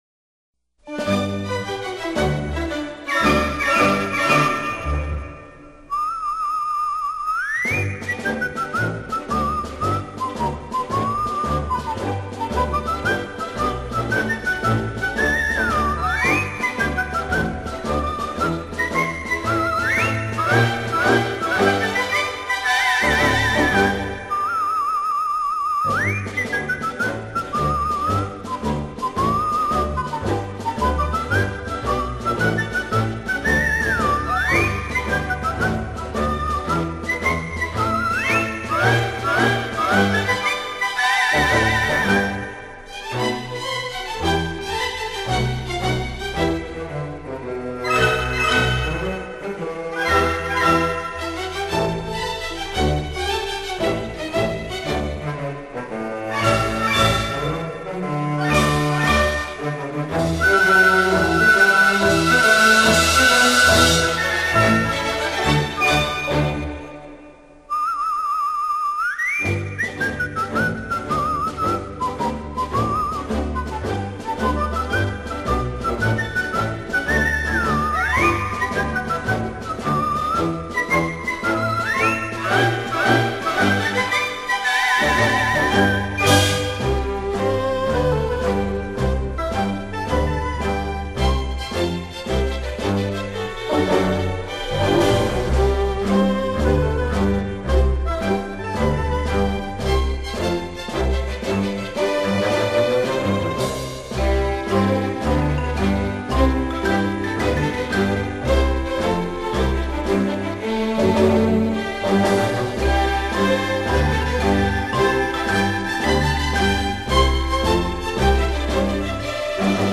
世界名曲《口哨与小狗》欣赏  《口哨与小狗》 又译《吹口哨的少年和小狗》 是美国作曲家普莱亚（1870—1943） 于1905年创作的一首通俗管弦乐小曲， 乐曲描绘了小主人边吹口哨边与心爱的小狗 在林荫道上漫步的情景。 曲调轻松、活泼、形象逼真、可爱。
 乐曲结构为 单三部曲式 主题 A: 主要以口哨为 主奏，旋律中有不少附点 音符及三连音，活泼而有弹性， 表达了小主人 轻松愉快的心情。   主题 B:口哨停止，由低音乐器演奏出雄壮有力的旋律，音色上和A段形成了鲜明的对比。